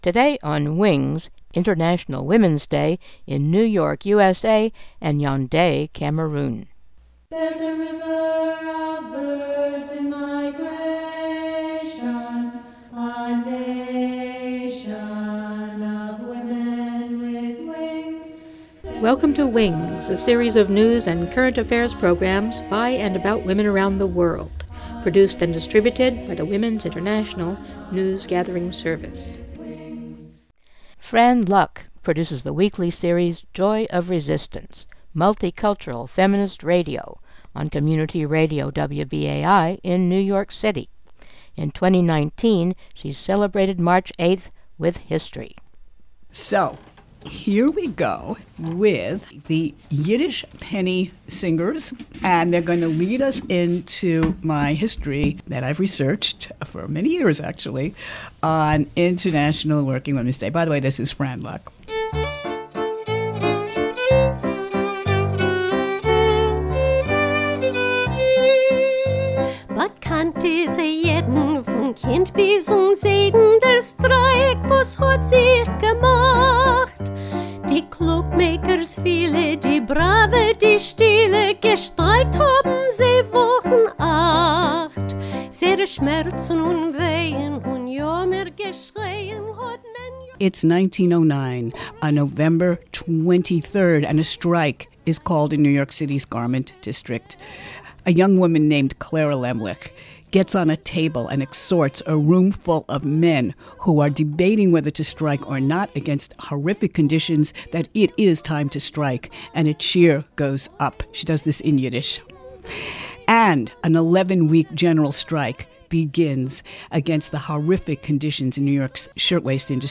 Radio coverage from New York USA and Yaounde Cameroon